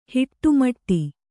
♪ hiṭṭu maṭṭi